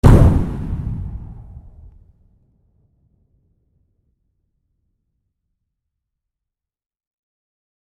Bang/Explosion Metallic
A bang battle bomb boom detonation explode explosion sound effect free sound royalty free Memes